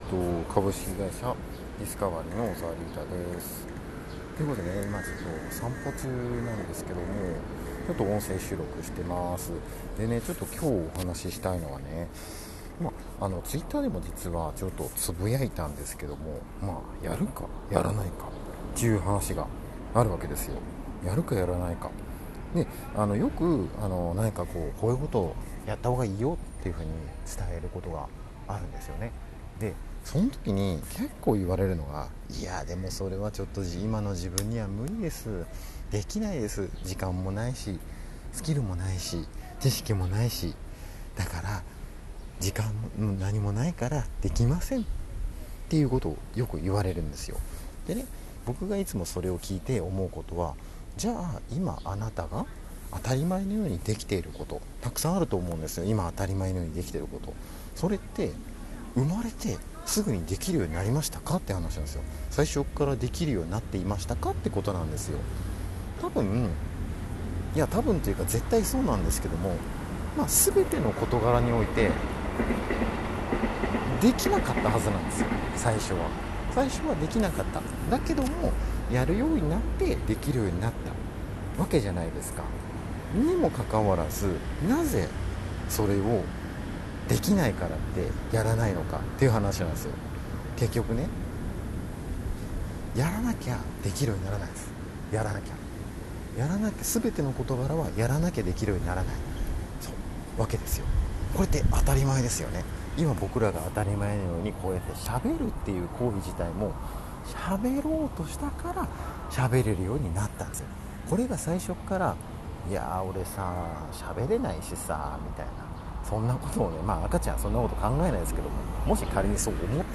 実際の回答音声の事例
あれ、雑音や環境音が多いな…と思ったあなたへ
「あれ、電車の音とか、車の音とか、環境音とか、雑音がかなり多くて聞きづらい部分があるな？」と。
実はこの音声、「散歩」しながら収録しております。散歩をすることで、考えがまとまったり、思考が活性化するからです。そうして散歩しながら収録したので、このように音が悪くなっております。雑音をカットしたりといったことも一切しておりません。